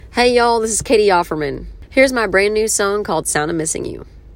LINER